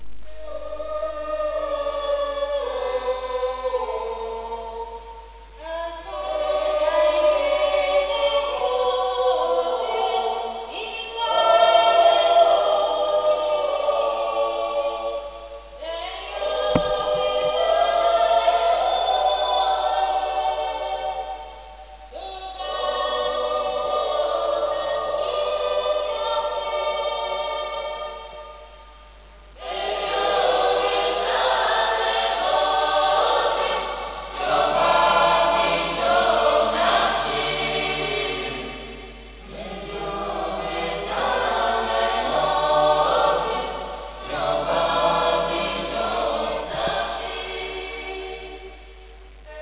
Canto popolare tramandato per via orale
(per sentire il canto corale)